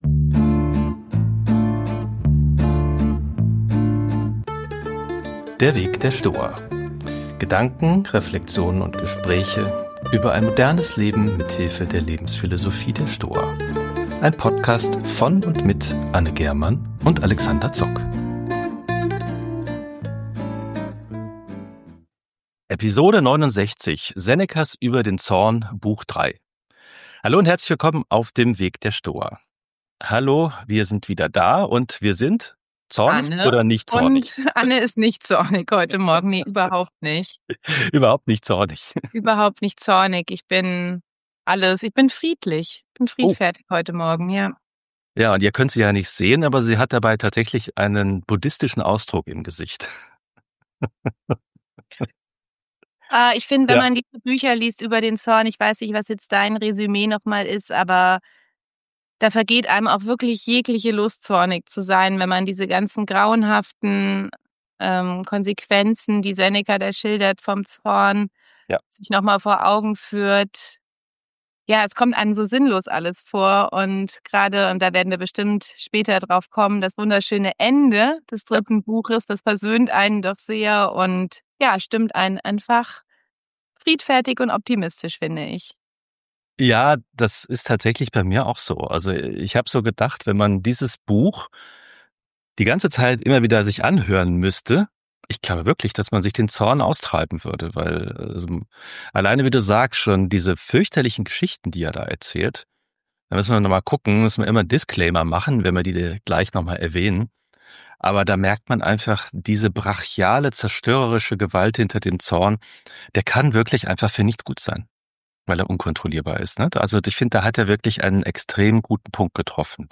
Gespräch über Senecas 3. Buch über den Zorn, über die Therapie des Zorns und andere Überlegungen zum Zorn.